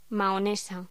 Locución: Mahonesa
voz